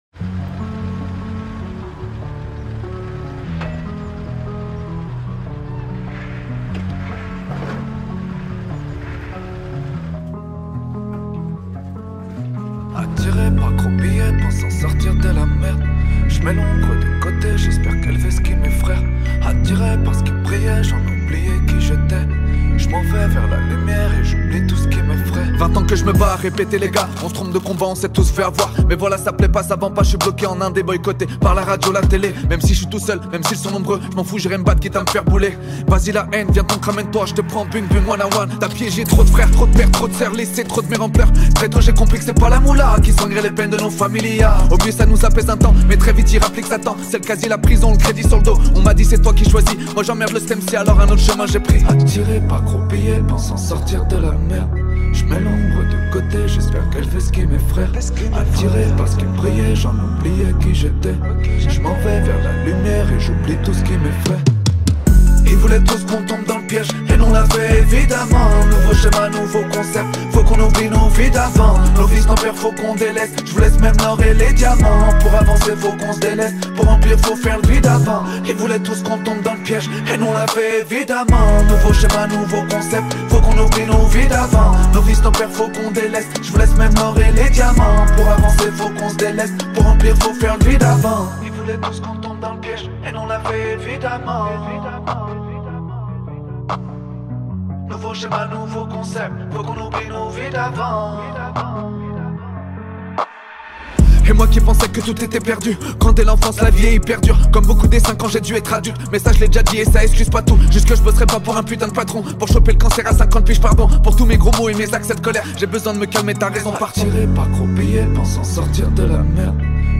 Emission spéciale sur Radio Ondaine pour la présentation de la Rue Des Artistes 2025